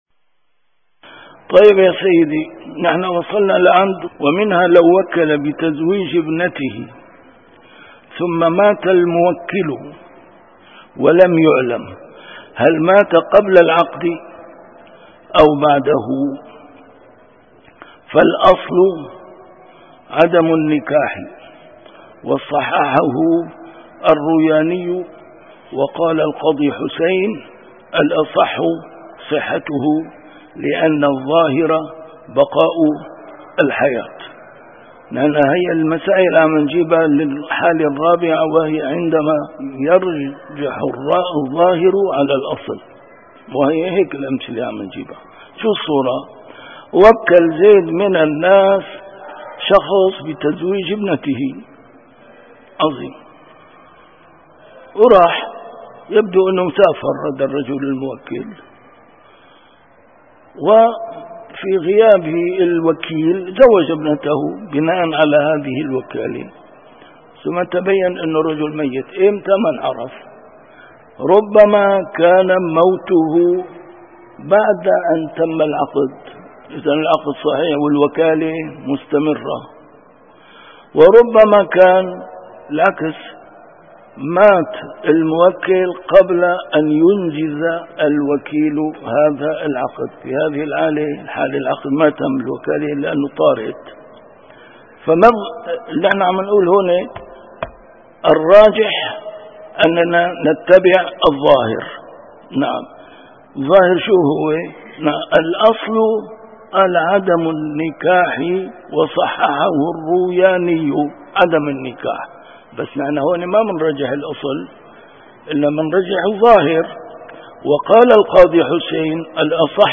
A MARTYR SCHOLAR: IMAM MUHAMMAD SAEED RAMADAN AL-BOUTI - الدروس العلمية - كتاب الأشباه والنظائر للإمام السيوطي - كتاب الأشباه والنظائر، الدرس التاسع والثلاثون: تعارض الأصل والظاهر - تعارض الأصلين.